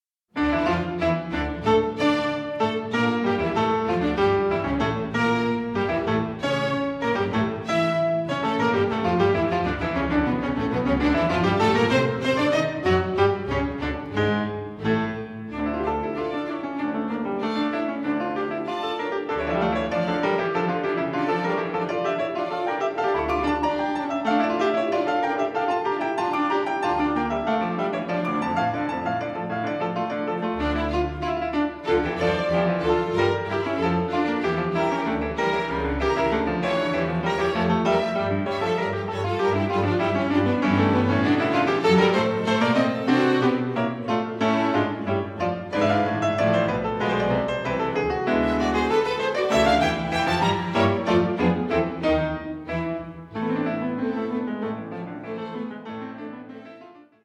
Stereo
Concerto No 1 in D minor for keyboard and strings